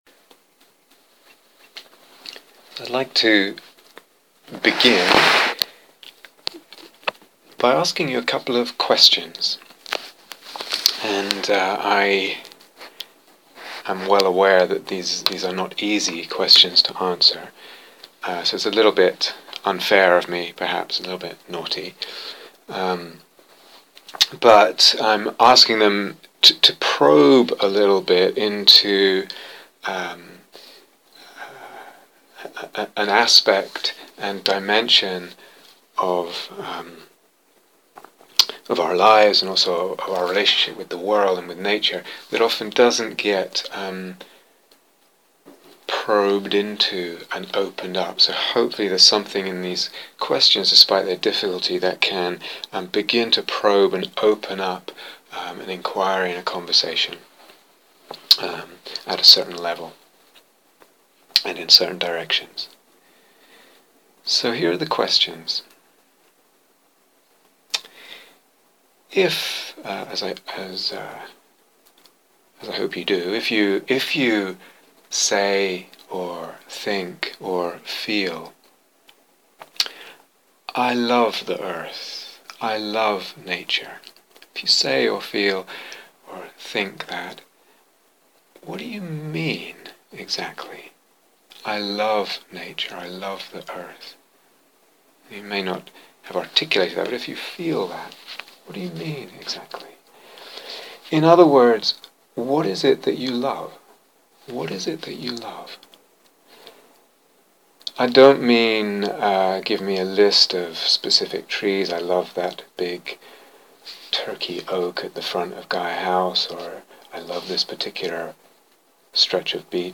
An Ecology of Love (Part 1 - Introduction) A talk about love, eros, mettā, and the Dharma; about our sense of the Earth, and a sense of the sacred.